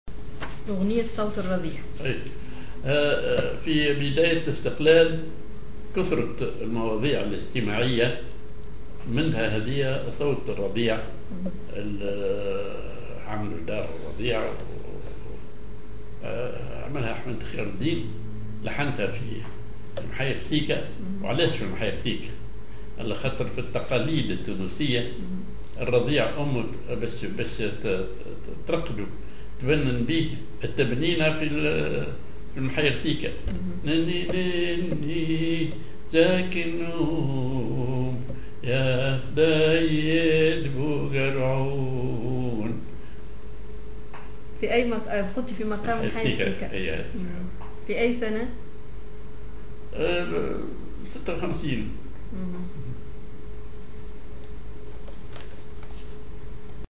Maqam ar محير صيكة
Rhythm ar مدور حوزي ثم مدور تونسي
genre نشيد